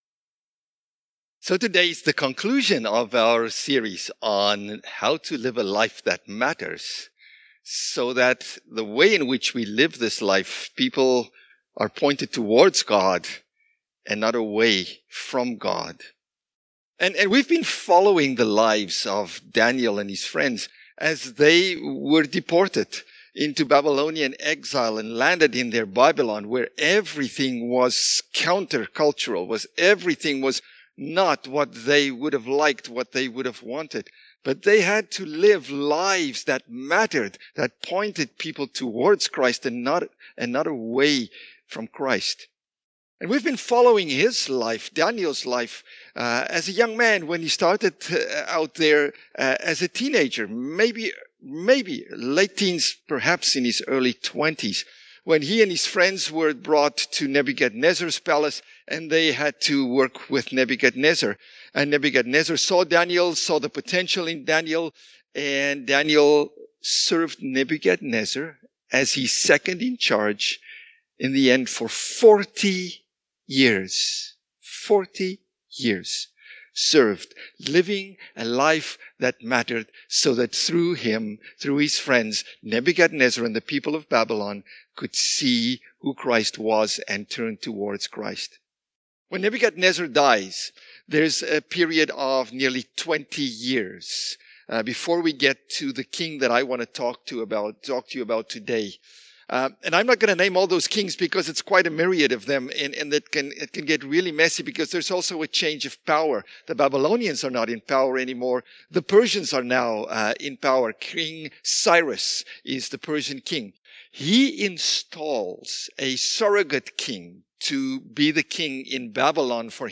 SermonMarch20.mp3